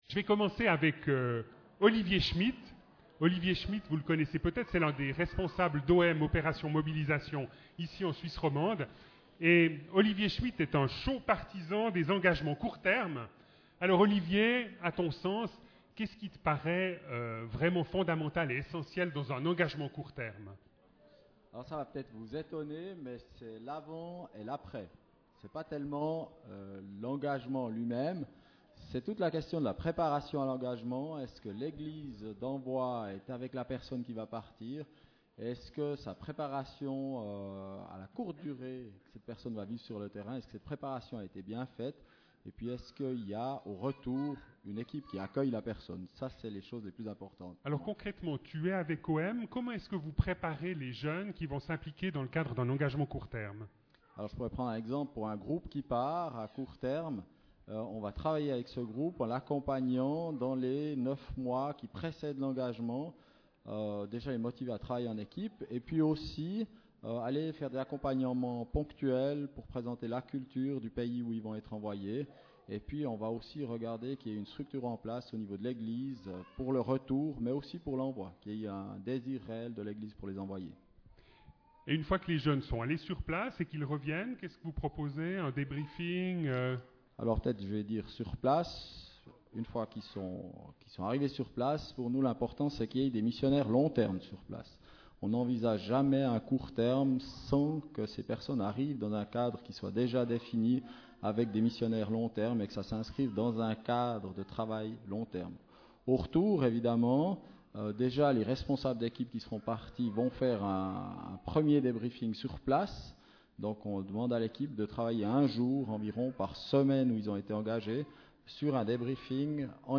EEM09_Table-ronde.mp3